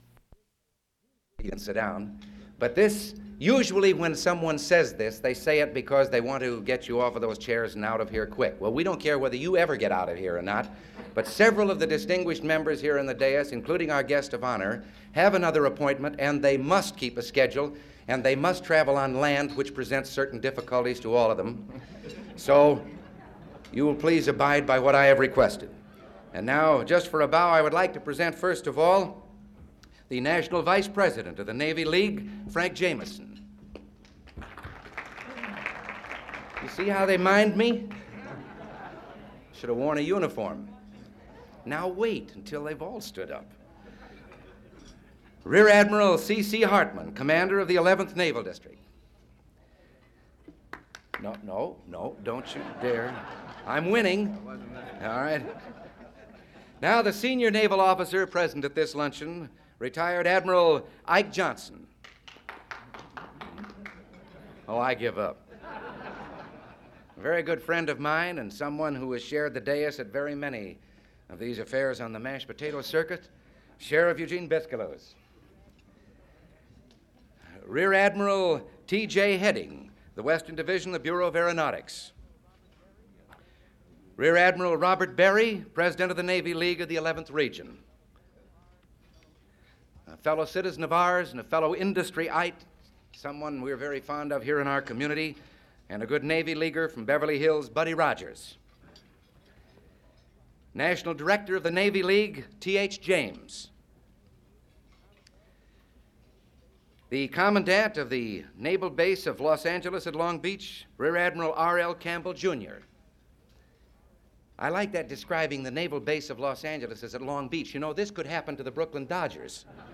Ronald Reagan’s remarks as MC of Luncheon for the Navy League in California
Reel to Reel Audio Format (CD copy).